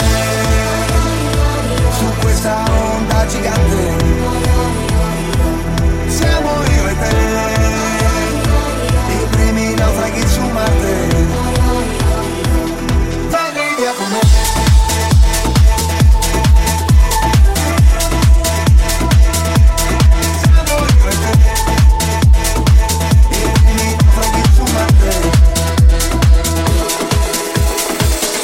Genere: pop,dance,afrobeat,house,latin,edm,remixhit